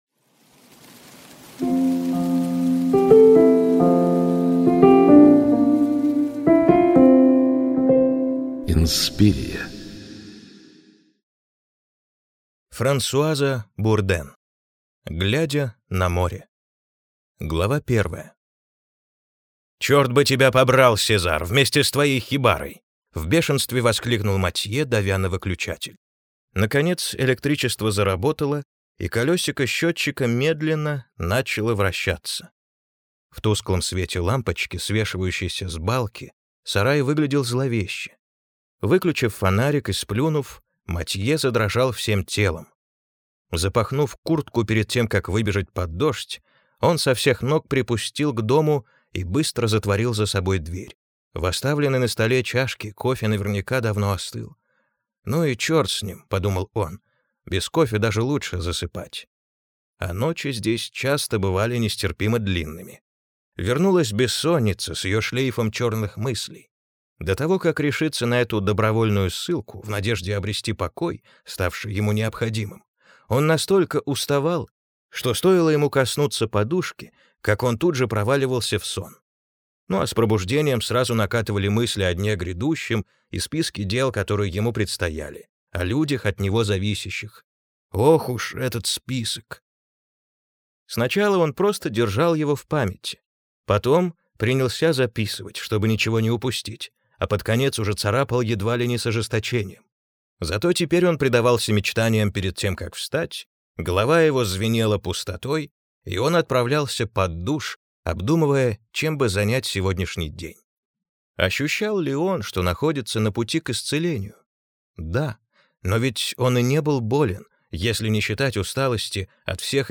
Аудиокнига Глядя на море | Библиотека аудиокниг